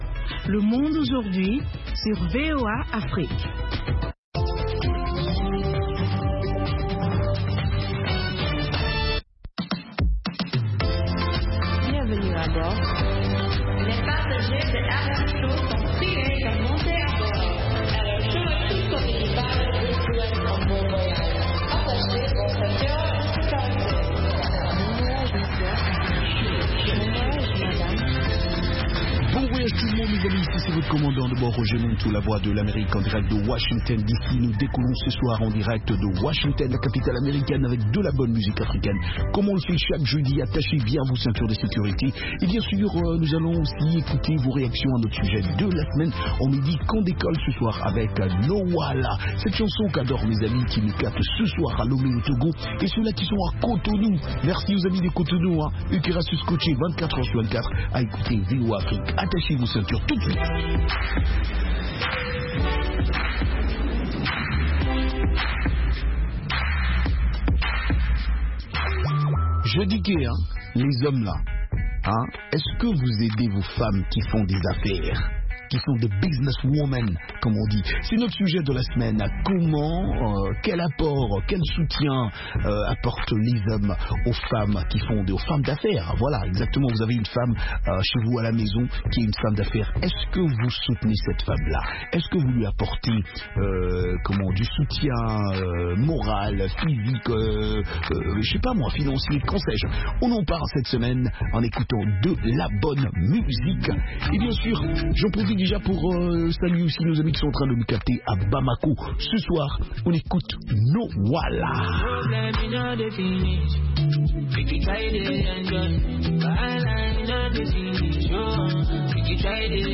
des reportages et interviews sur des événements et spectacles africains aux USA ou en Afrique.